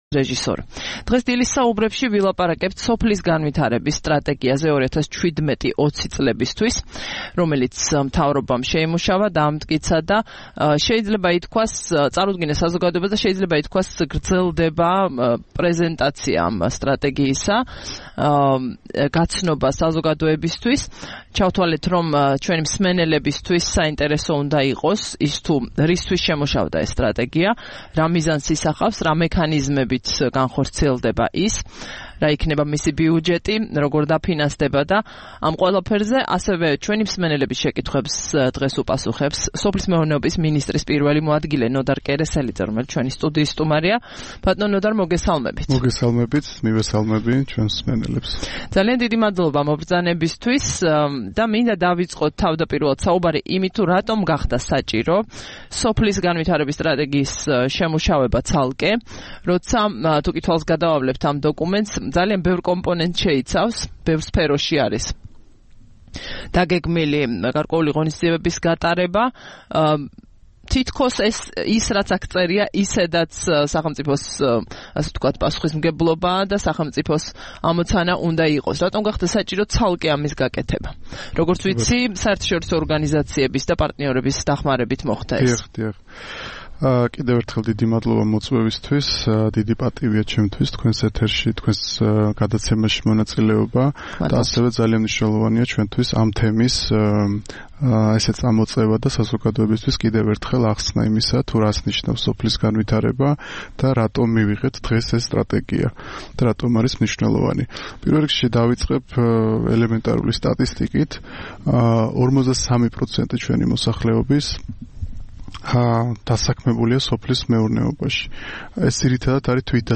13 აპრილს რადიო თავისუფლების „დილის საუბრების“ სტუმარი იყო სოფლის მეურნეობის მინისტრის პირველი მოადგილე ნოდარ კერესელიძე.